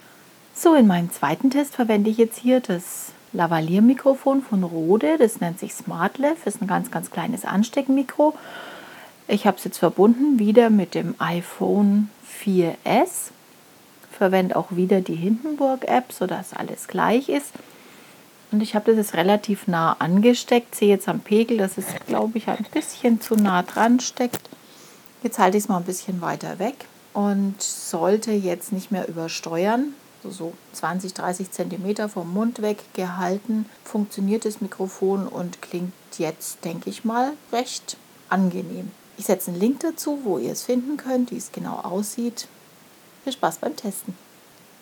Test mit Smartlav, iPhone, Hindenburg-App
Testaufnahmen Smartlav von Roede
Gelbe-Tasche-Test-Smartlav.mp3